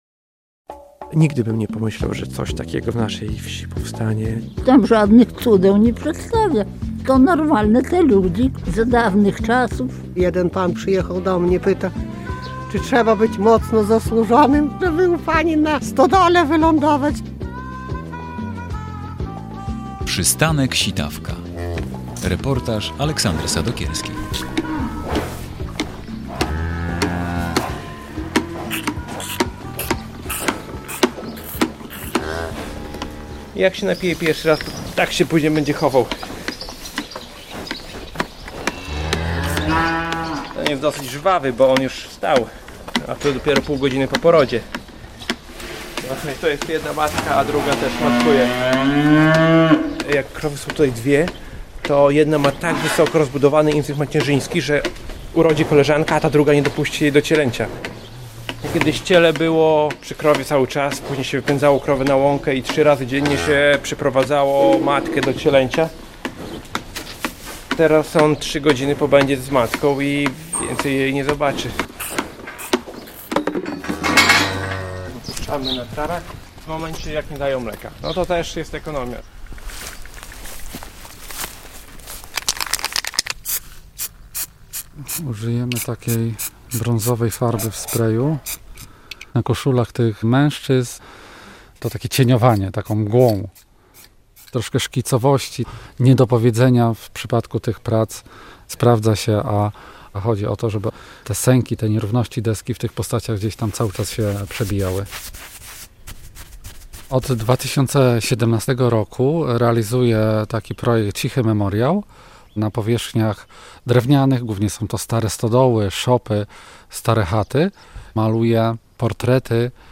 Reportaż | "Przystanek Sitawka"